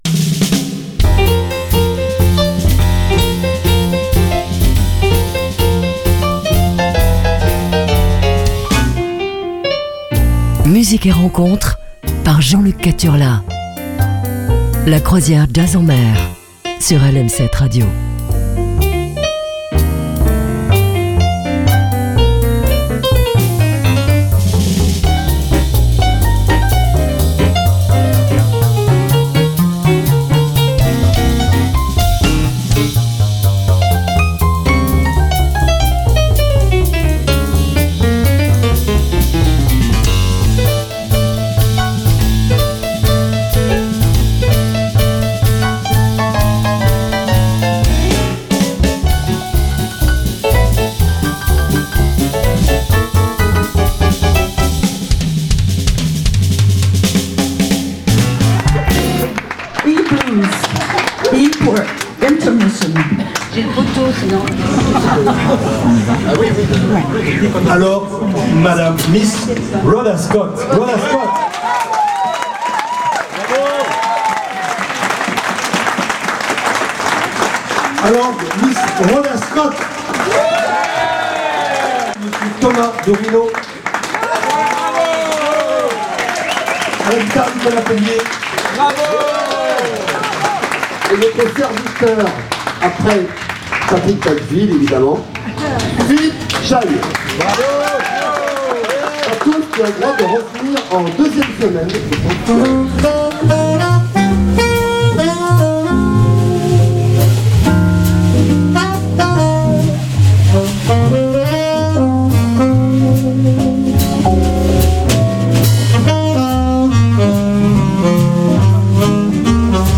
rythmes jazzy